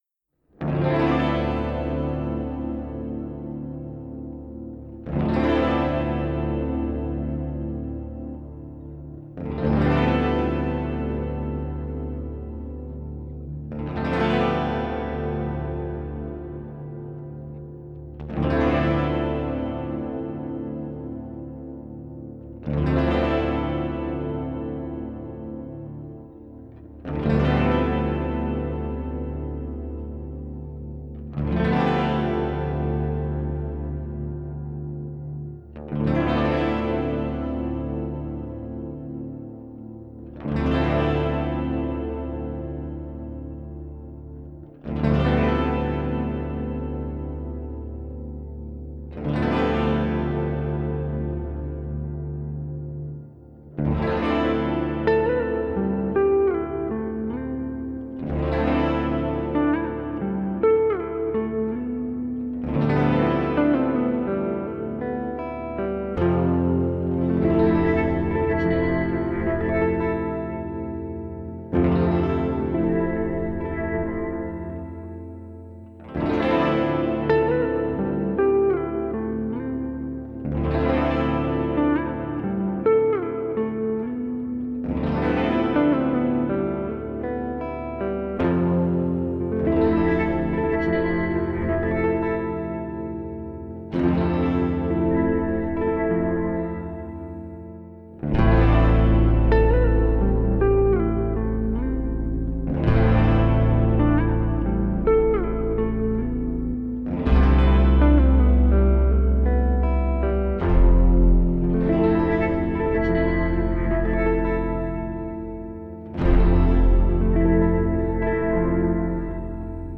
4/4, noire = 50, (drop Ré).
Le morceau est ternaire.
NB : les enregistrements sont joués avec un tempo un peu plus rapide, noire = 54.
[0'53] [1'16] [1'38] les 3 thèmes de 5 mesures avec quelques ebows,
[2'00] l'accord de break qui lance la partie aquatique.
Ce morceau est joué tou·tes ensemble.
Ensuite, vient toute une ambiance aquatique avec des baleines, des bloop, etc.